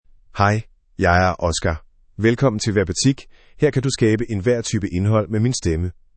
MaleDanish (Denmark)
Oscar — Male Danish AI voice
Oscar is a male AI voice for Danish (Denmark).
Voice sample
Listen to Oscar's male Danish voice.
Oscar delivers clear pronunciation with authentic Denmark Danish intonation, making your content sound professionally produced.